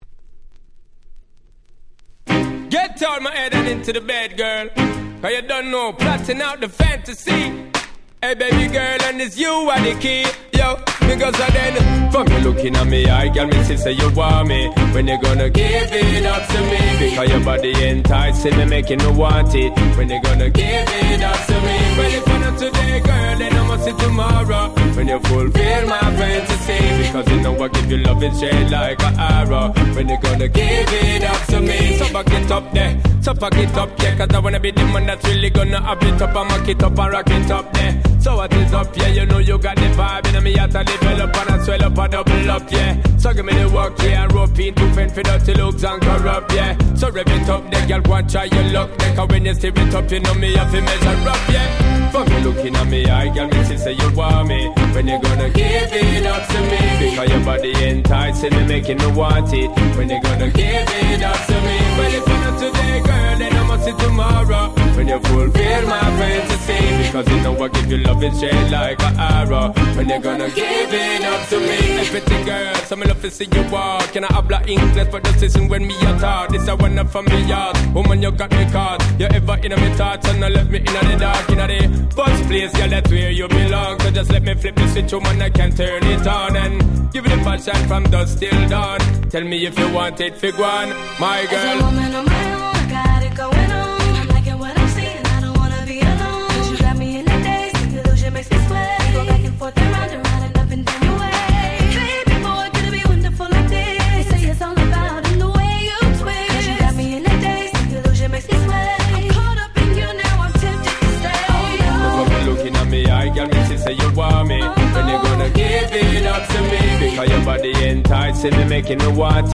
06' Smash Hit Dancehall Reggae !!